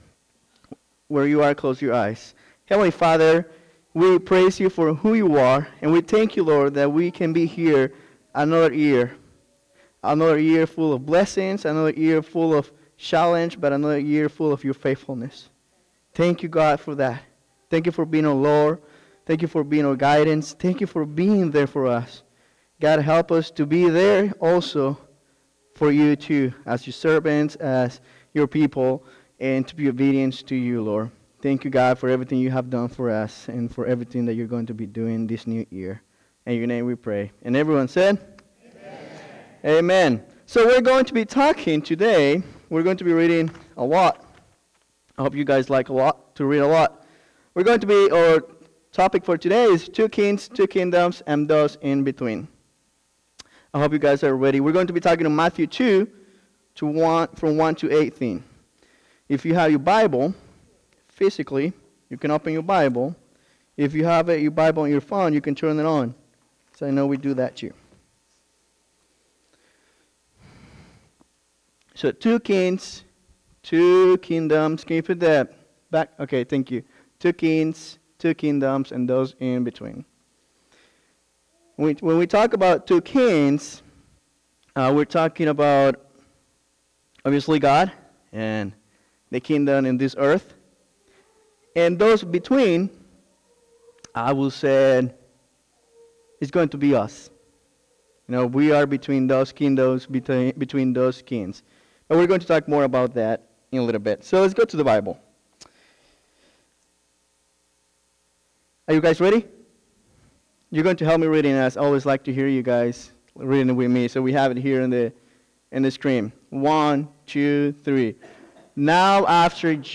Today, on this Sunday of Epiphany,